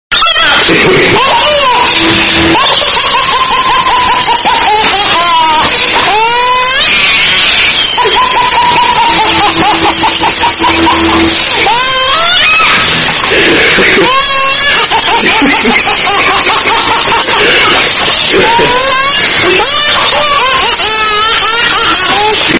Áudio Bebê Rindo (Completo) – Estourado
Categoria: Risadas
Essa risada gostosa e contagiante vai deixar seus memes e conversas muito mais engraçadas.
audio-bebe-rindo-completo-estourado-pt-www_tiengdong_com.mp3